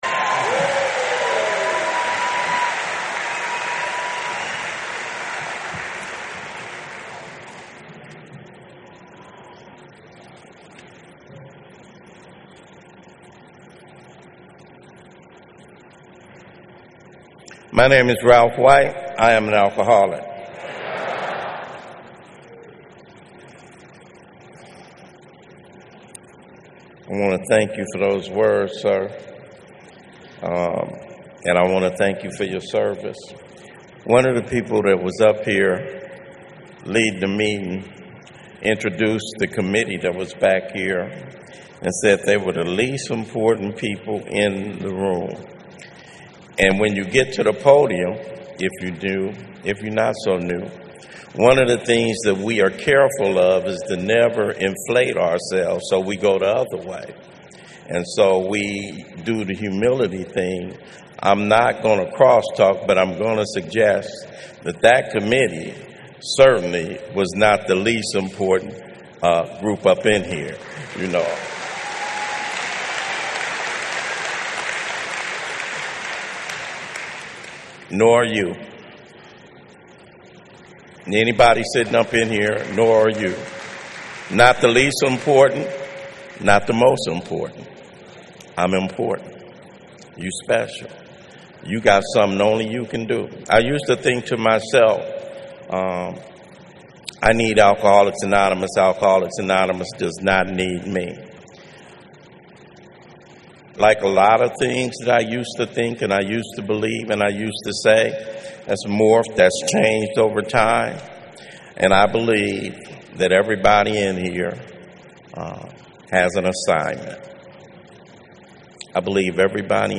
GA @ The Boomerang Group of CA in April 2024